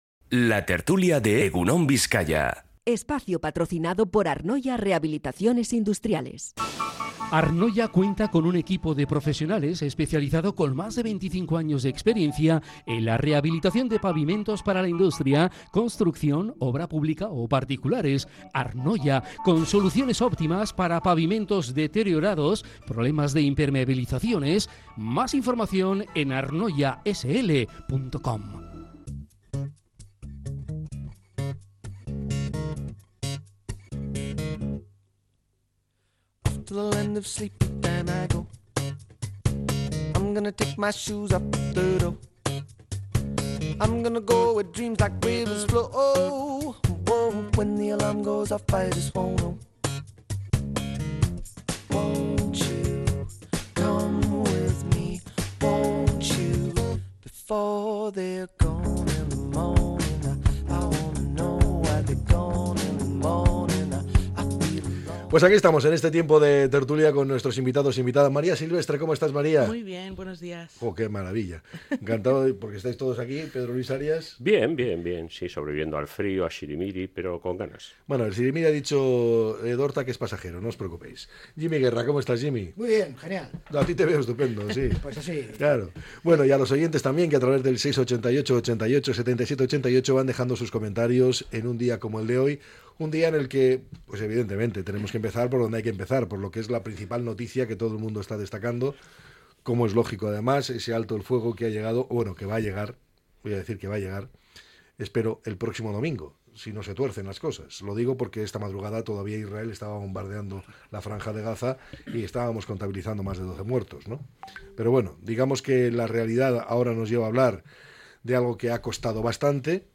La tertulia 16-01-25.